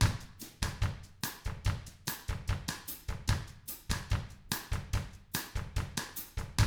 146BOSSAT1-L.wav